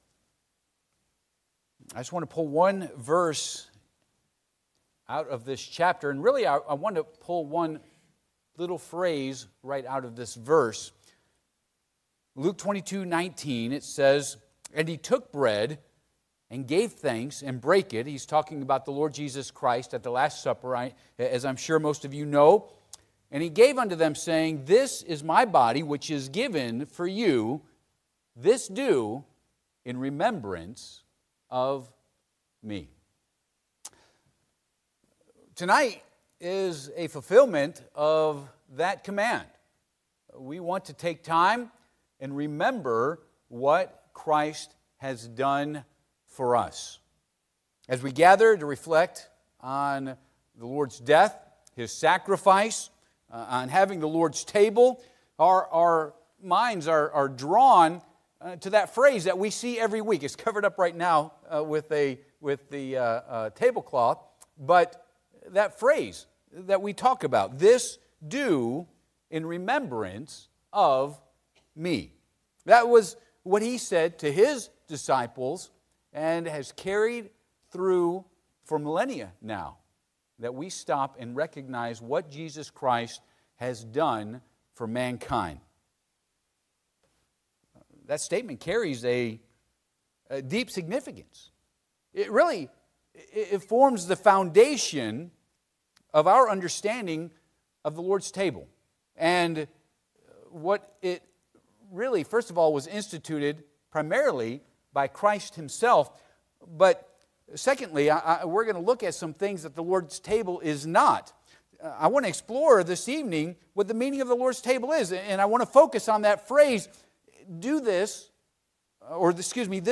Luke 22:19 Service Type: Sunday PM Topics: The Lord's Table « Does Jesus Care?